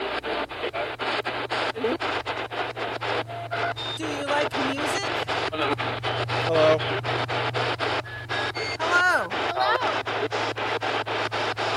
WFMU GB #3 -  Here everybody is saying hello!
At the 6 second mark a male voice replies "Hello" and has a distinct accent like it might be British.